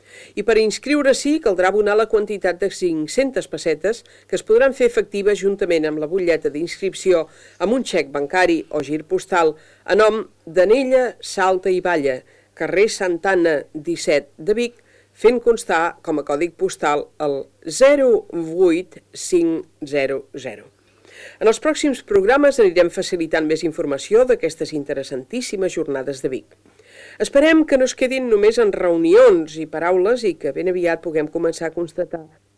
L'entitat Anella salta i balla organitza les jornades Sardana i Futur, a Vic Gènere radiofònic Musical